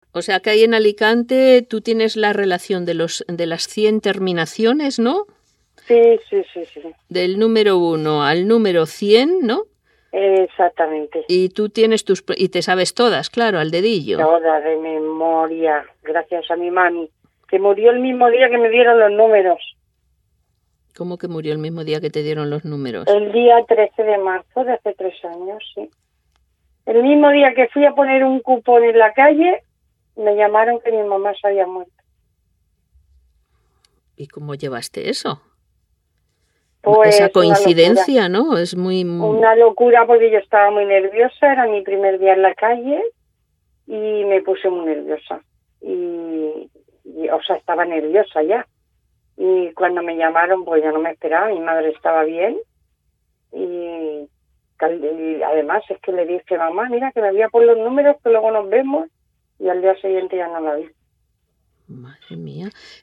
a golpe de recuerdos. formato MP3 audio(1,20 MB) “Mi inicio en el trabajo fue muy malo, muy mal -reitera- pero todo se puso muy bien enseguida porque yo creo que ella me ayuda.